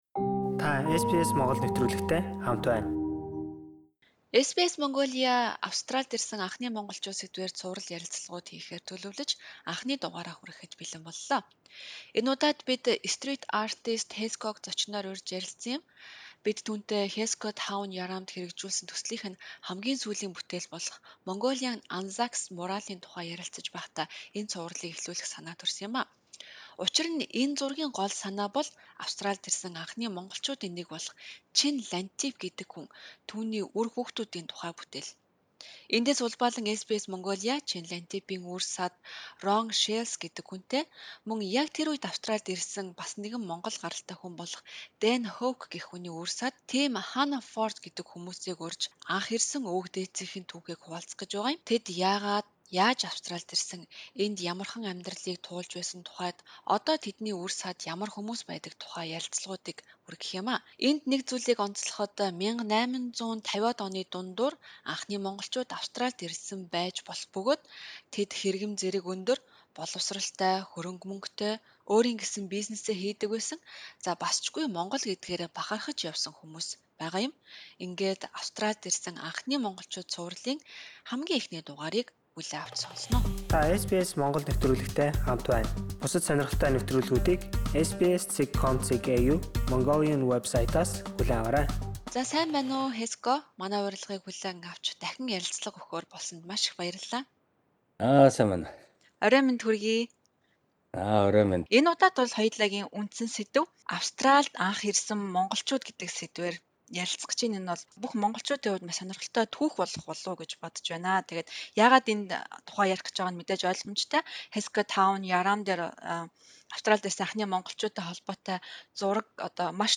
ярилцлаа